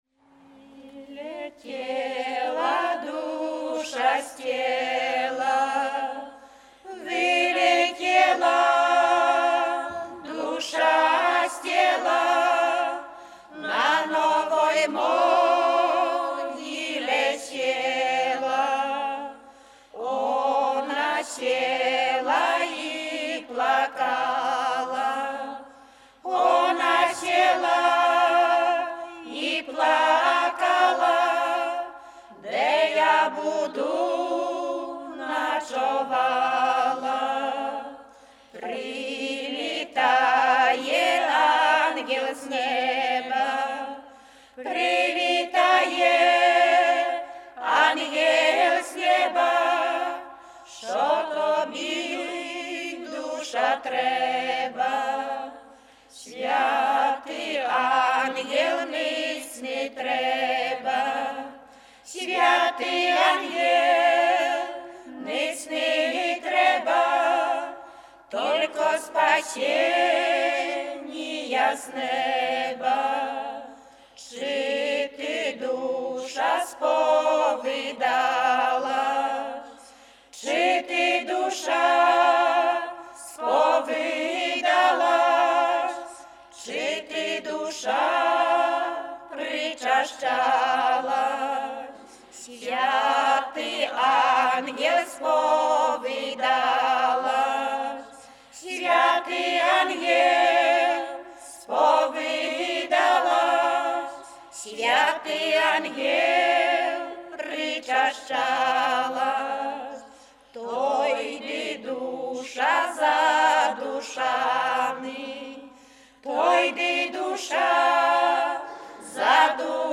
Śpiewaczki z Dobrywody
Podlasie
Pogrzebowa
W wymowie Ł wymawiane jako przedniojęzykowo-zębowe;
nabożne pogrzebowe prawosławne